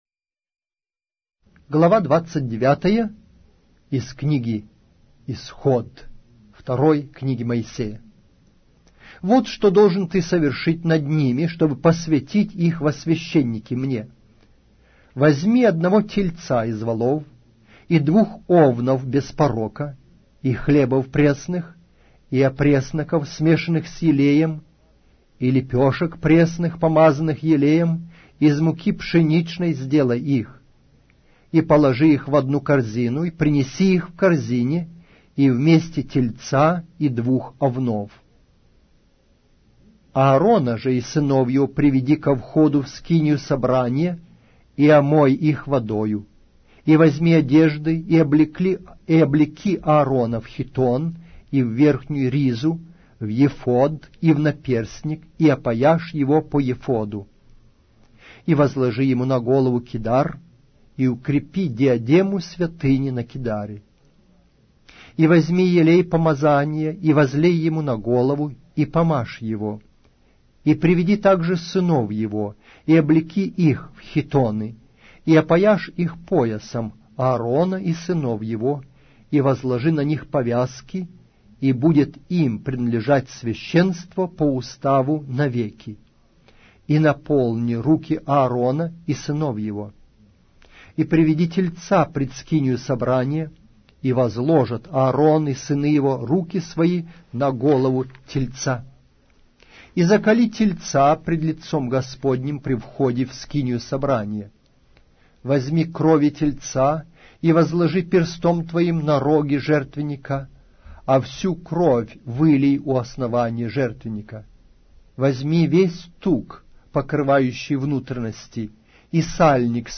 Аудиокнига: Книга 2-я Моисея.